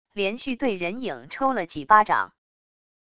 连续对人影抽了几巴掌wav音频